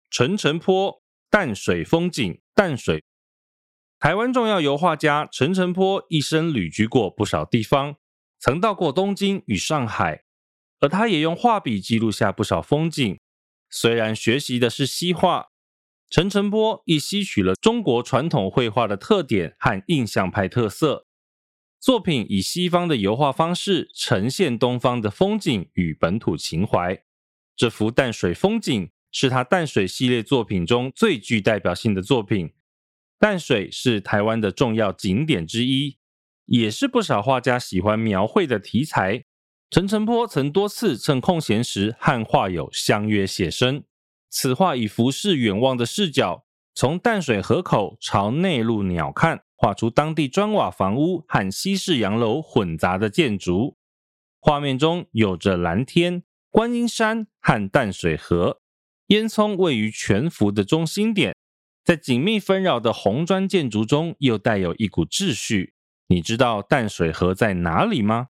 語音導覽